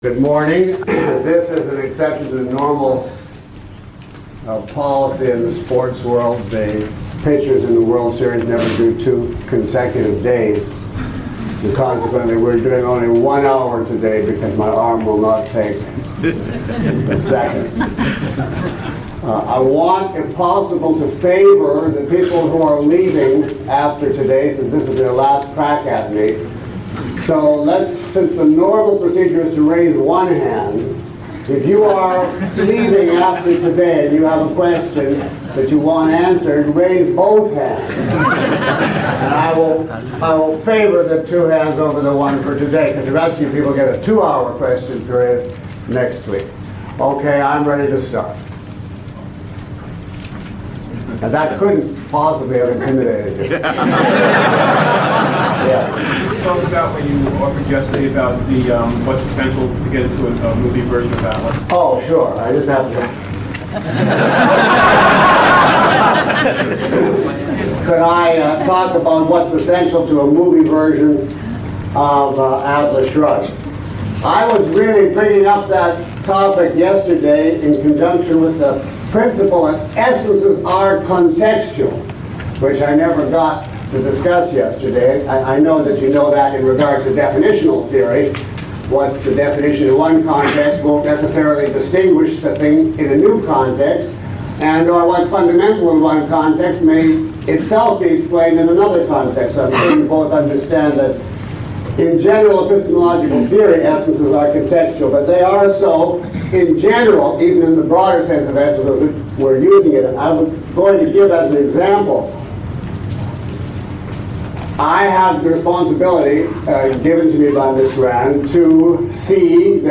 A question and answer session for course attendees.